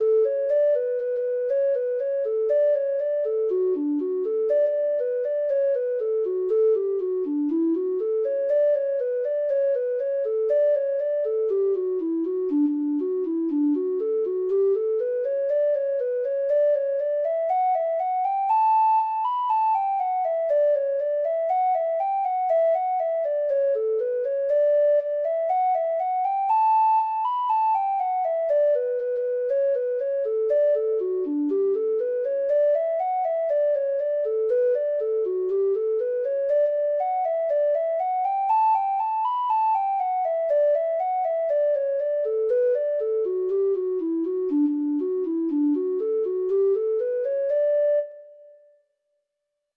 Traditional Music of unknown author.
Reels
Irish